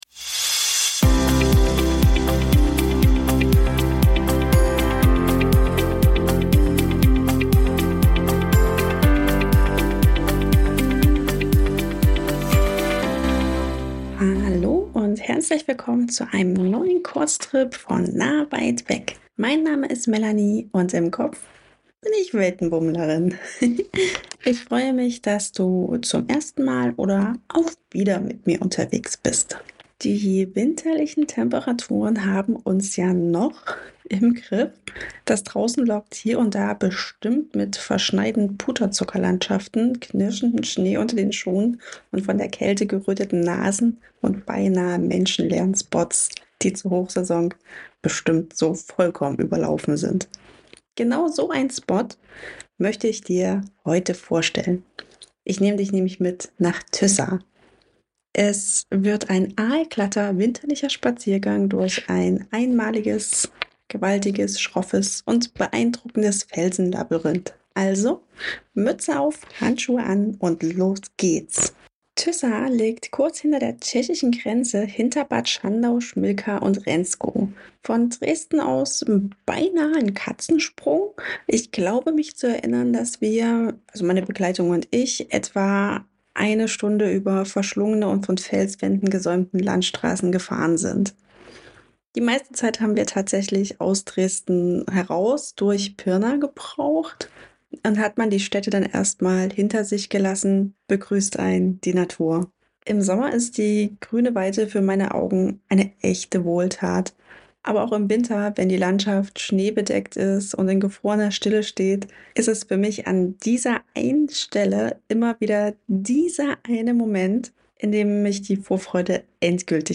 In dieser Episode nehme ich dich mit ins verschneite Felsenlabyrinth der Tyssaer Wände, kurz hinter der tschechischen Grenze. Erlebe mit mir die mystische Stille, knirschenden Schnee und spannende Sagen, die dieses einzigartige Winterparadies umgeben.